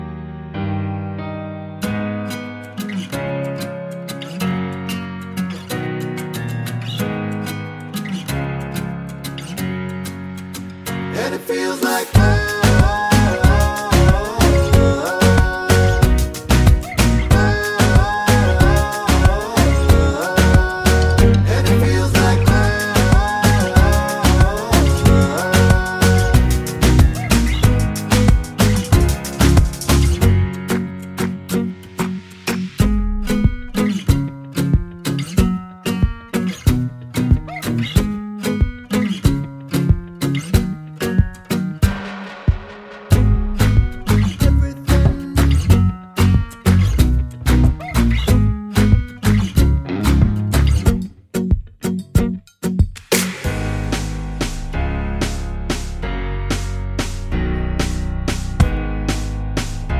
With Clean Backing Vocals Pop (2010s) 3:29 Buy £1.50